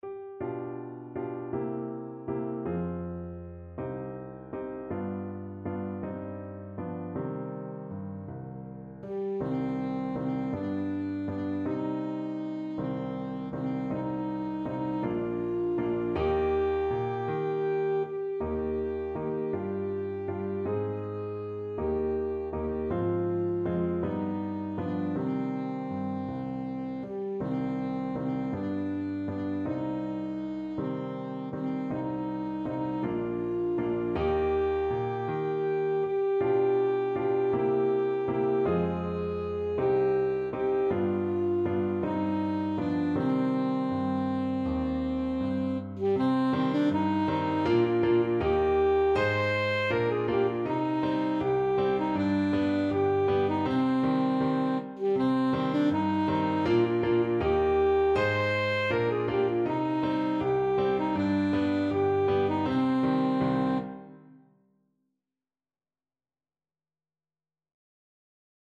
Alto Saxophone
6/8 (View more 6/8 Music)
Andante
G4-C6